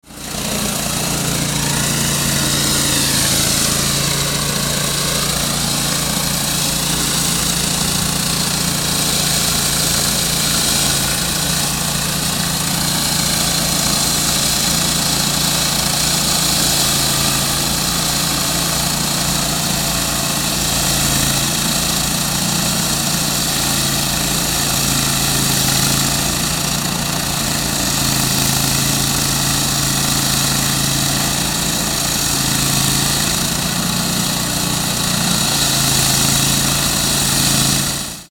Concrete Cutting Sound Effect
Cutting an asphalt road using a road saw. Construction site noises.
Concrete-cutting-sound-effect.mp3